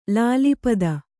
♪ lāli pada